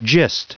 Prononciation du mot gist en anglais (fichier audio)
Prononciation du mot : gist